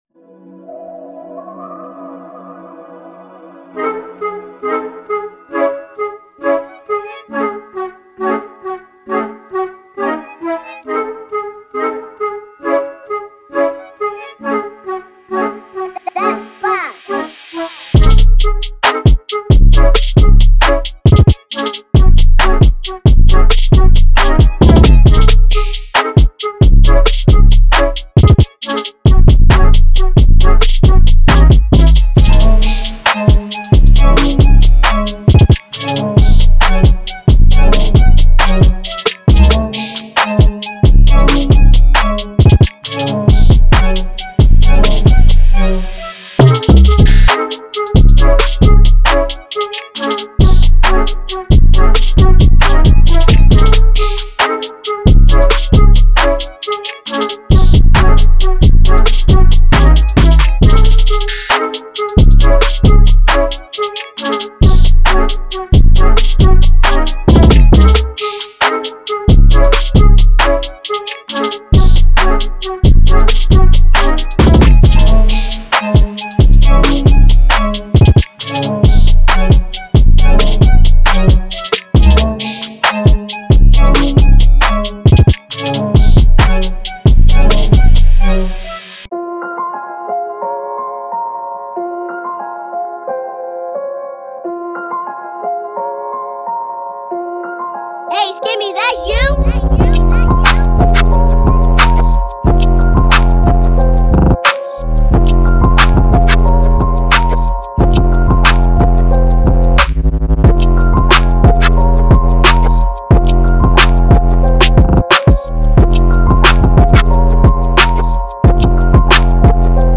又保持了干净而又新颖。
.9个拍手
-11个底鼓
.-31个长笛循环
.-10个圆号循环
.20个钢琴旋律
.30个弹拨循环
.11个陷波吉他循环